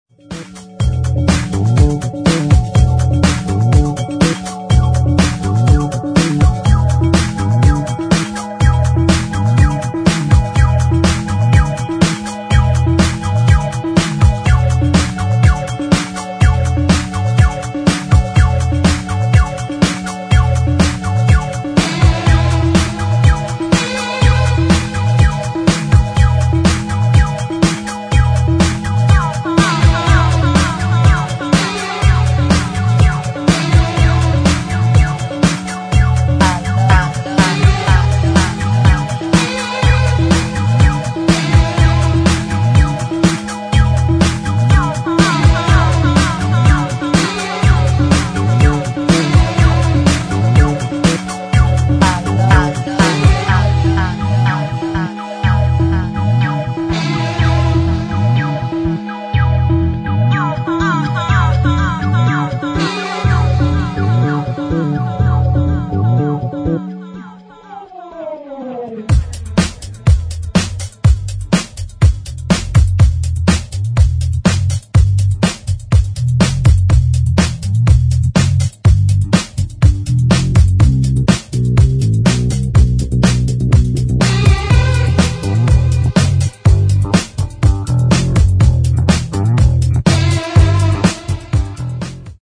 [ HOUSE / DISCO / EDIT ]